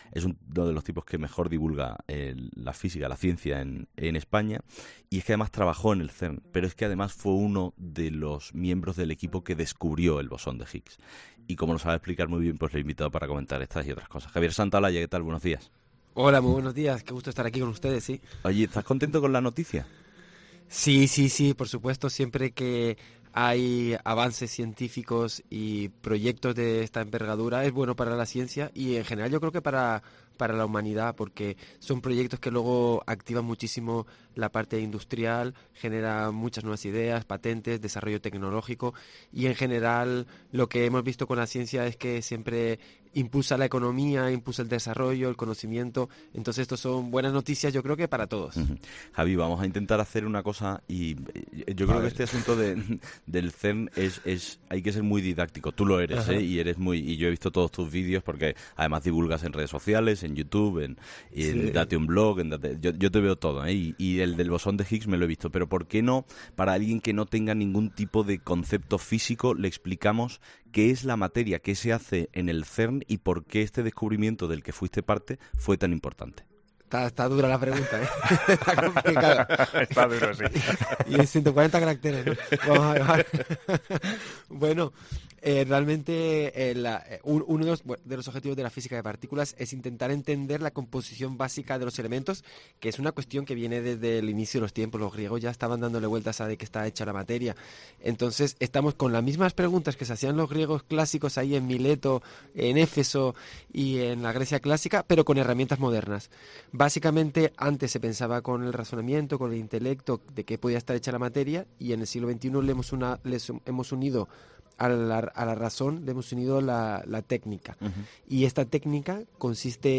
El científico y divulgador Javier Santaolalla, en Herrera en COPE